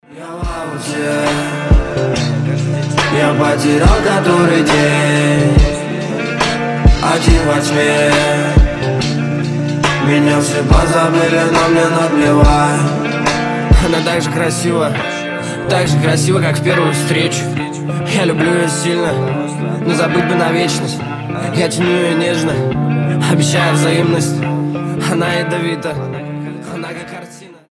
рэп , лирика